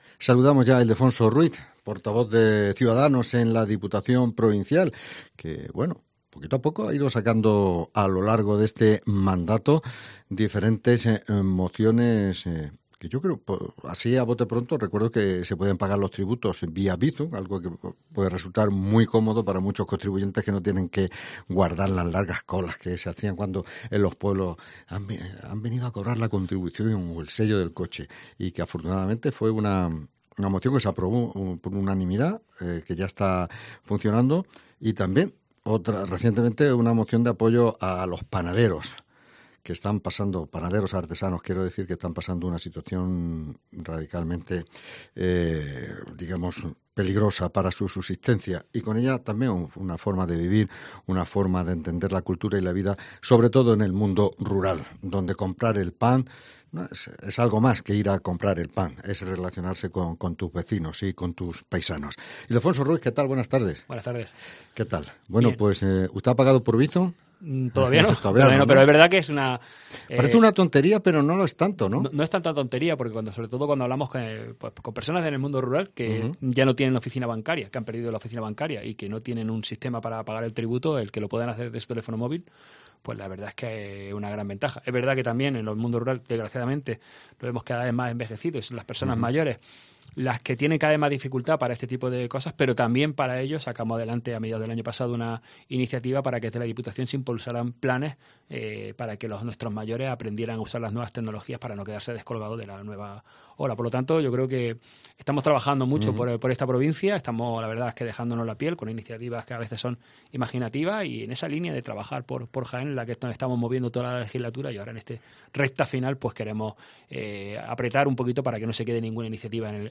AUDIO: El portravoz de CS en la Diputación de Jaén ha pasado por los micrófonos de COPE Jaén. entre otros asuntos hemos abordado...
LA ENTREVISTA